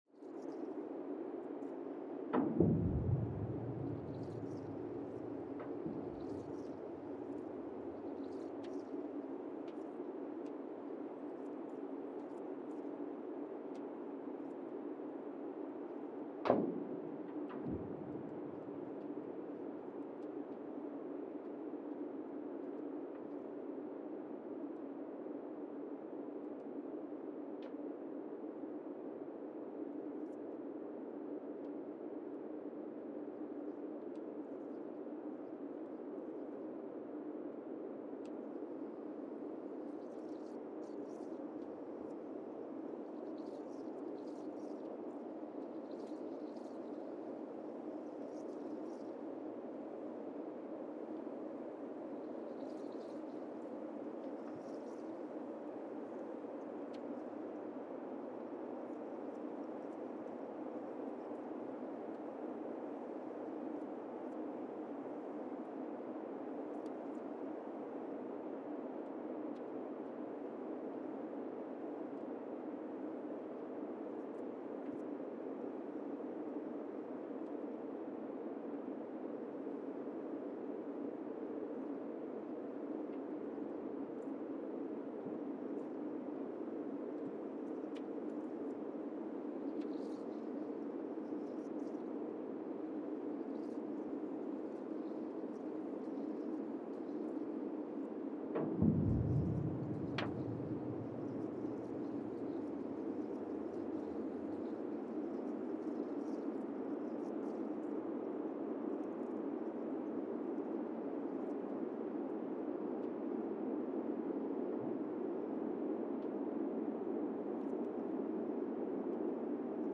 Monasavu, Fiji (seismic) archived on November 4, 2019
Sensor : Teledyne Geotech KS-54000 borehole 3 component system
Speedup : ×1,800 (transposed up about 11 octaves)
Loop duration (audio) : 05:36 (stereo)